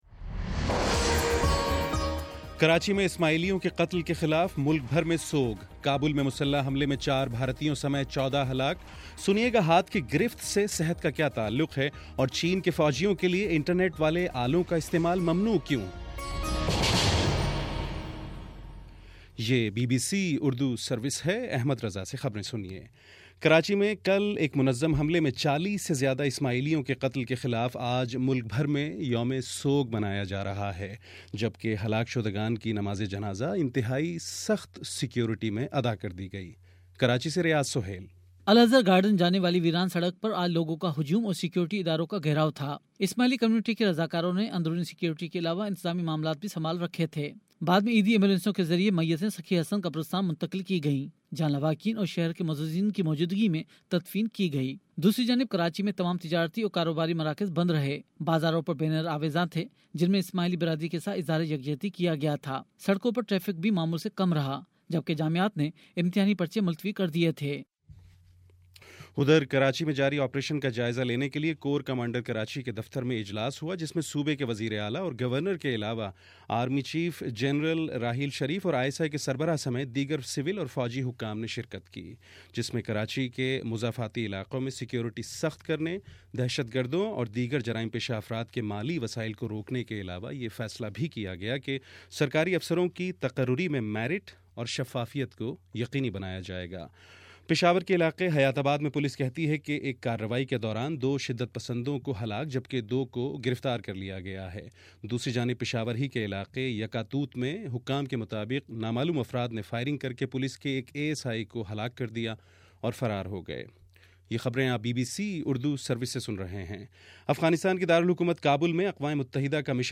مئی14: شام پانچ بجے کا نیوز بُلیٹن